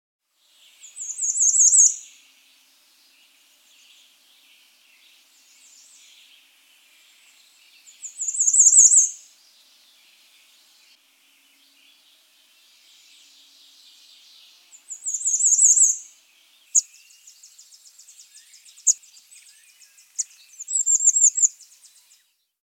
Bay-breasted Warbler
Bird Sound
Song very high-pitched "seetzy, seetzy, seetzy."
Bay-breastedWarbler.mp3